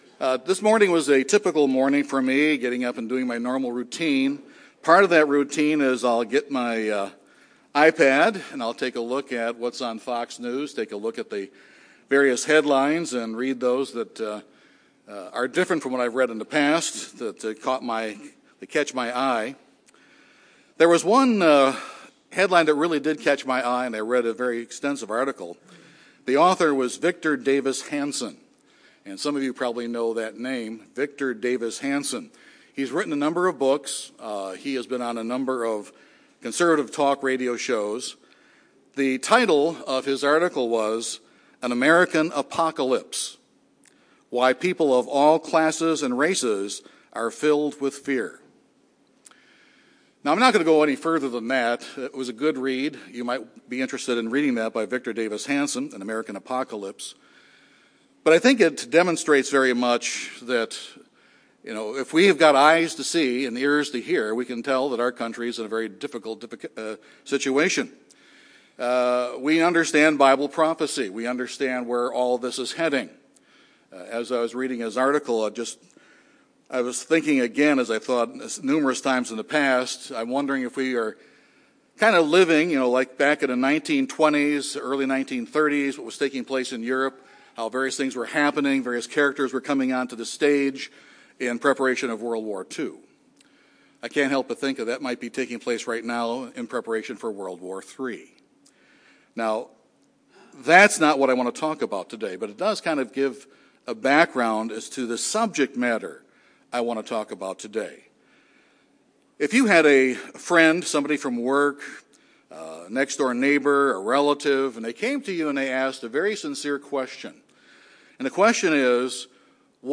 What metrics can be used to determine how responsive we are to God's Spirit? This sermon will discuss three areas that can be used to see how we are doing in this regard.